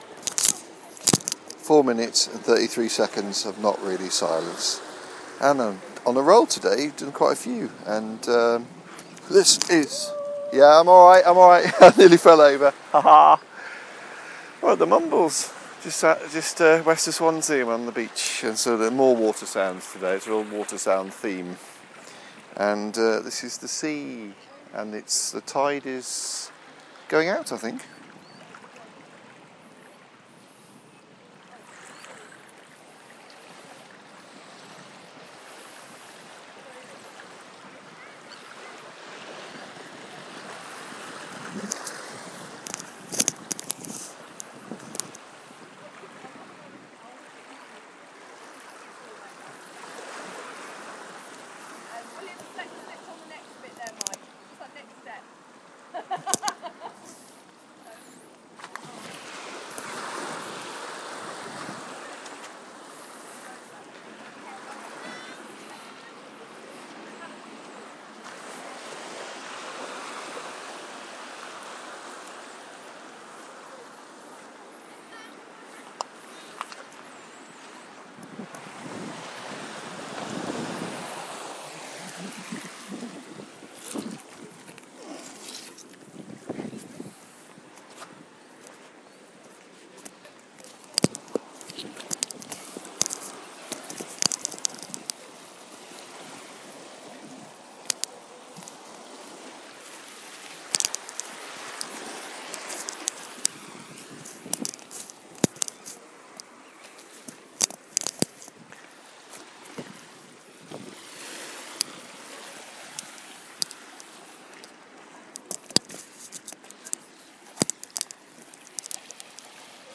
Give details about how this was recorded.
4m33s of Mumbles Coastline